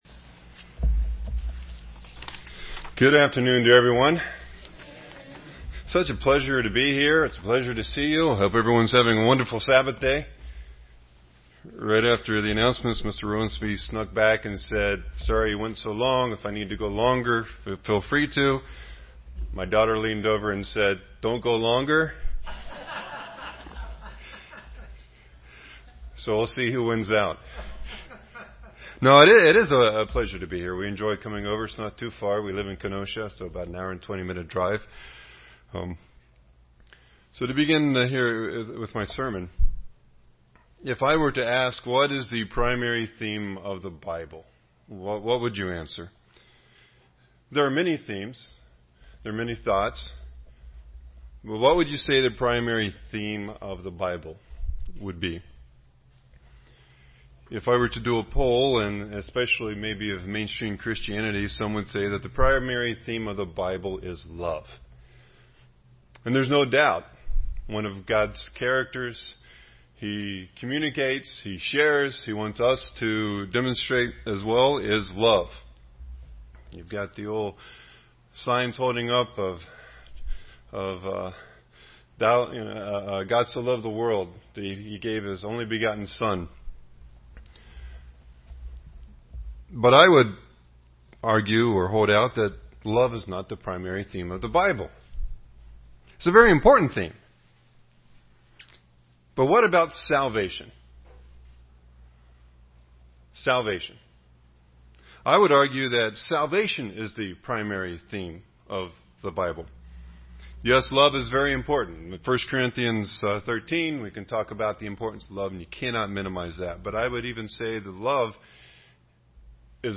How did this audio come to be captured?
Given in Beloit, WI